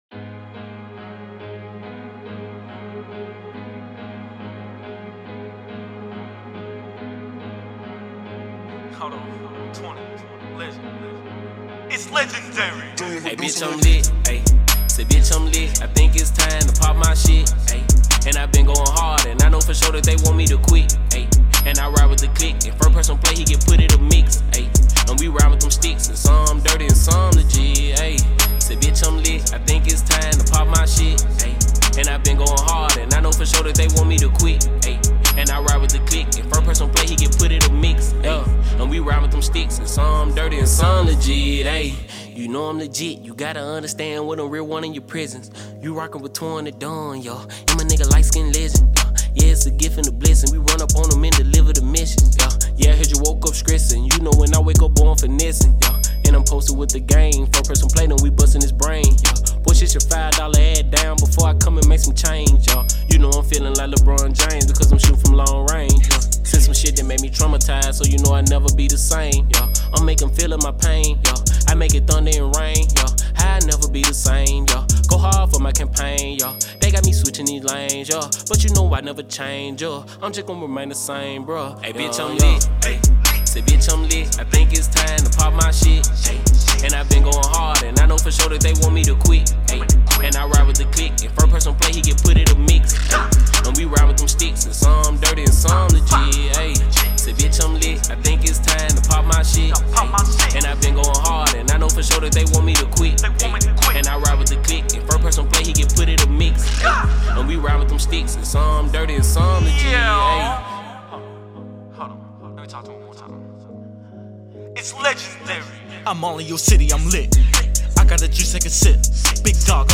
Rap(Music), Hip-hop, Popular music—Tennessee—Memphis, African Americans—Music, Memphis(Tenn.)—Social life and customs